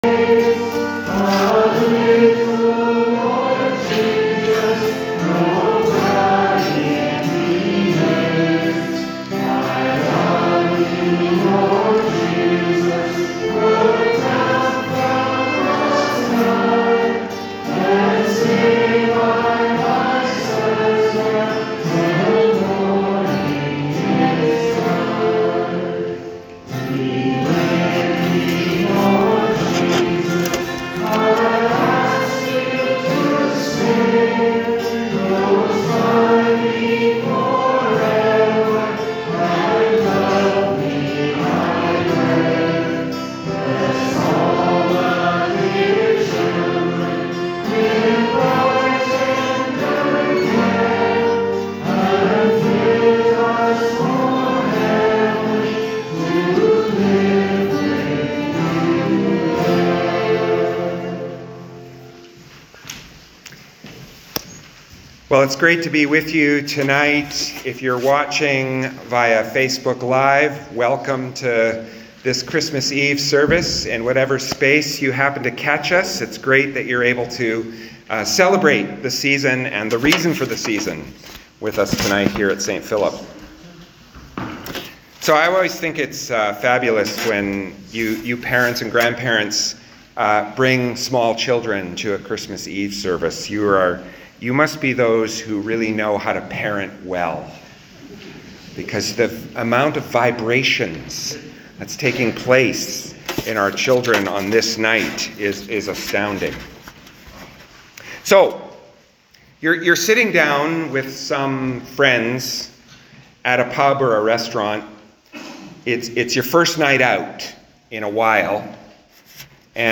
Sermons | St Philip Anglican Church